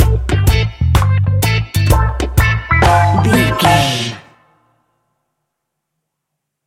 Classic reggae music with that skank bounce reggae feeling.
Aeolian/Minor
instrumentals
laid back
chilled
off beat
drums
skank guitar
hammond organ
percussion
horns